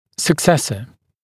[sək’sesə][сэк’сэсэ]преемник; зуб-преемник, т.е. постоянный зуб, который прорезывается на месте временного